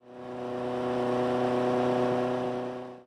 ogg / general / highway / oldcar / tovertake4.ogg